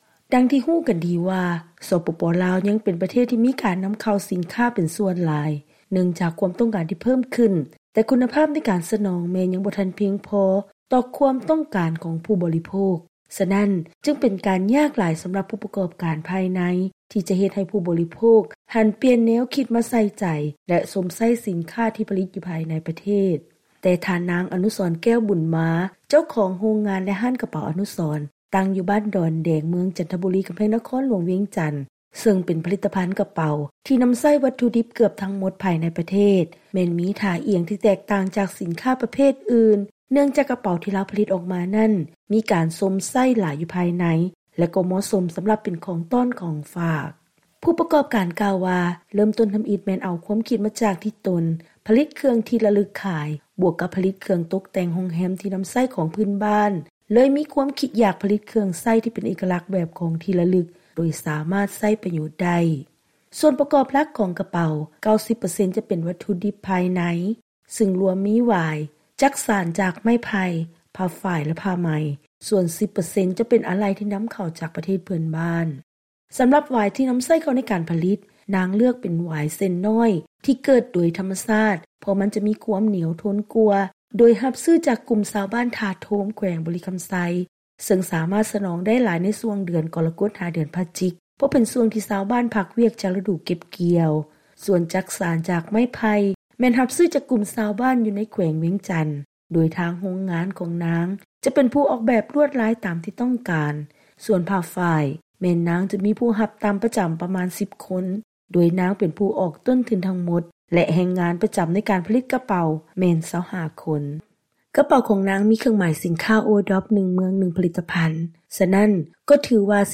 ເຊີນຟັງລາຍງານກ່ຽວກັບ ຜະລິດຕະພັນ ຫັດຖະກໍາກະເປົາ